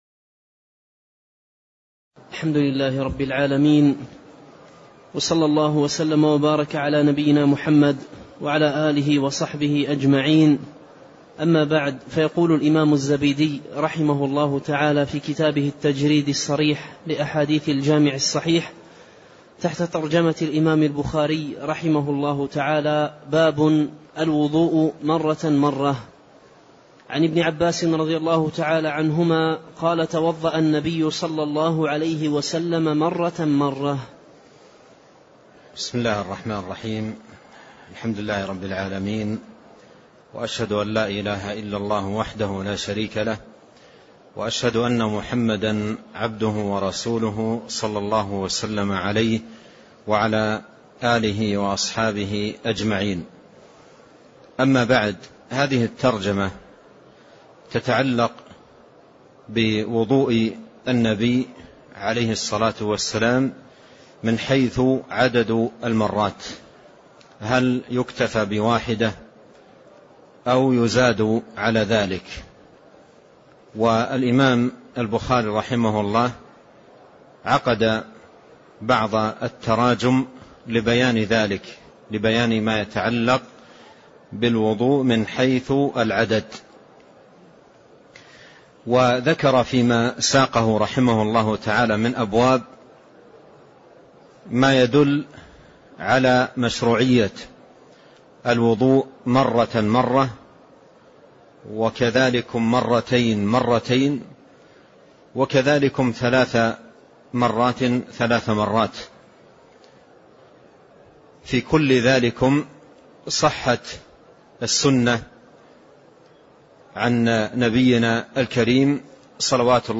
تاريخ النشر ٢٣ جمادى الأولى ١٤٣٣ هـ المكان: المسجد النبوي الشيخ: فضيلة الشيخ عبد الرزاق بن عبد المحسن البدر فضيلة الشيخ عبد الرزاق بن عبد المحسن البدر باب الوضوء مرةً مرة (03)